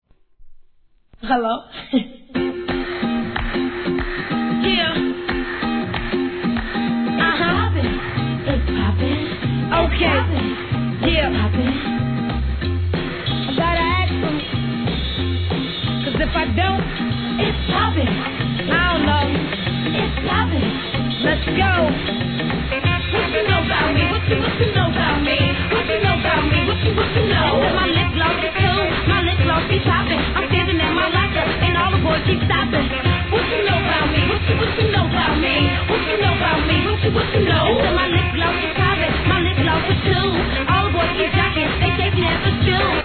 12inch
1. HIP HOP/R&B